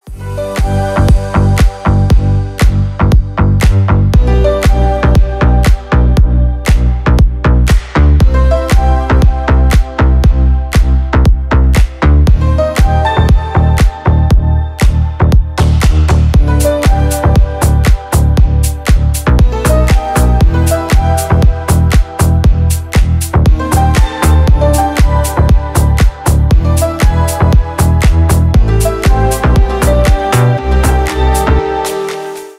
Поп Музыка
клубные # без слов